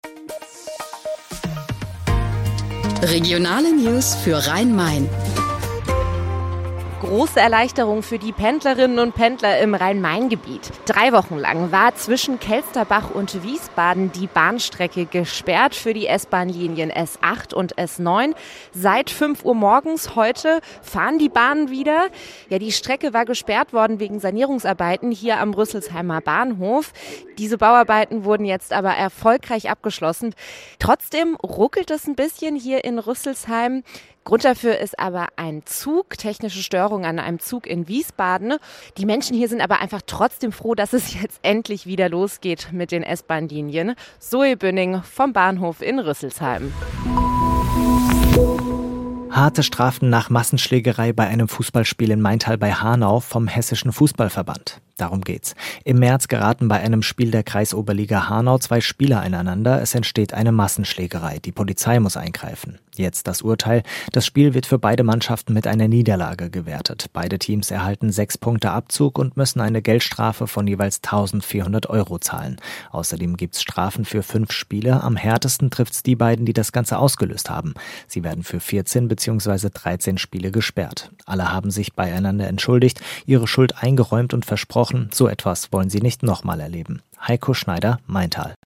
Mittags eine aktuelle Reportage des Studios Frankfurt für die Region